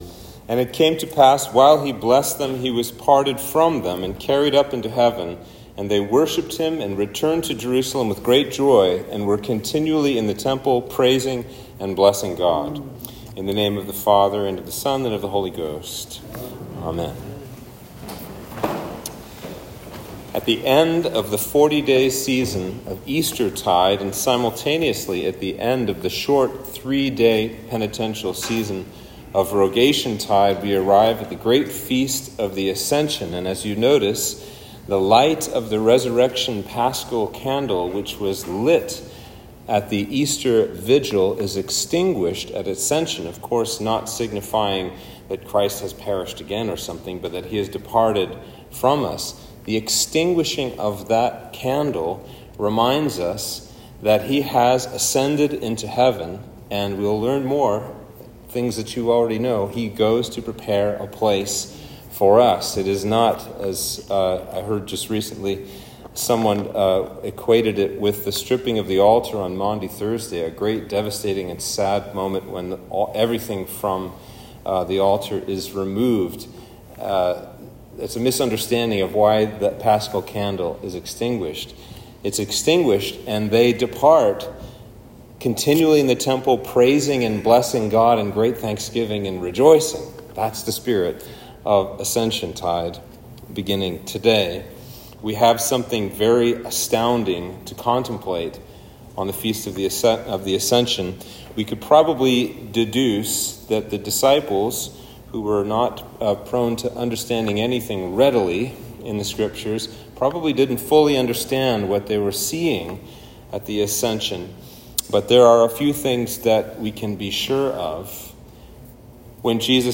Sermon for Ascension